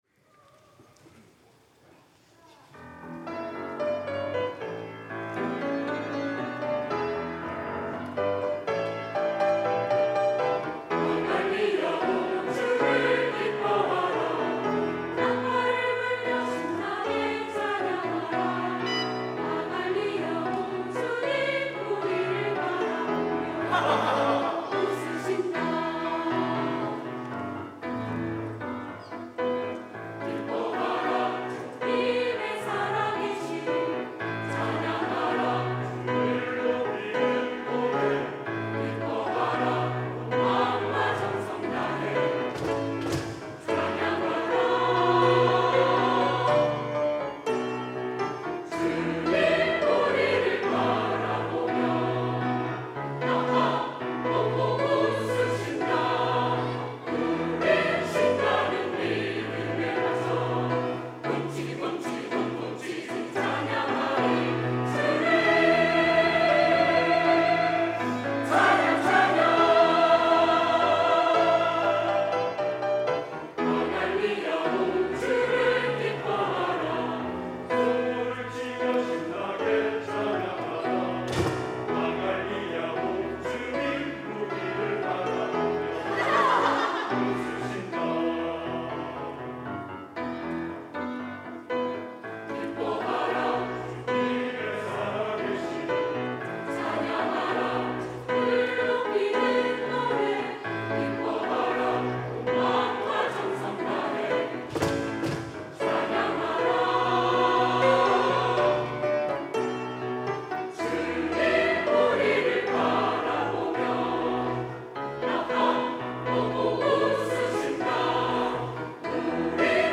할렐루야(주일2부) - 아갈리아오
찬양대